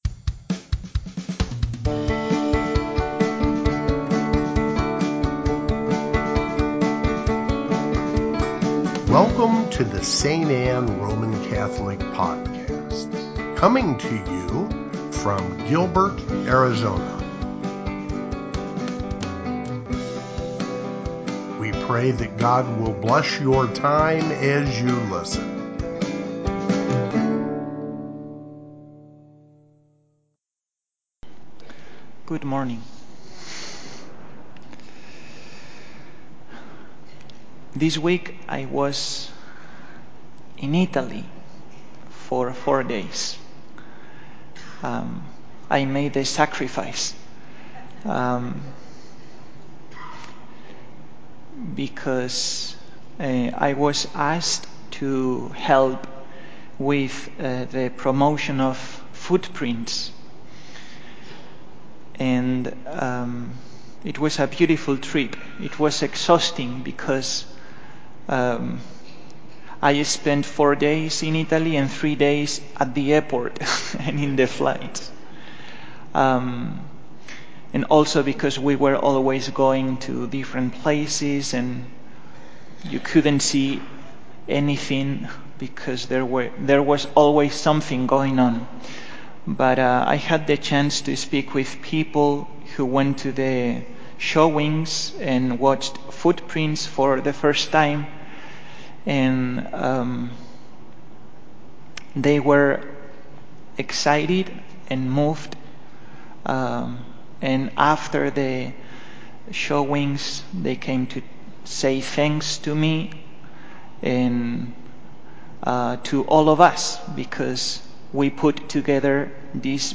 Fifth Sunday of Lent (Homily) | St. Anne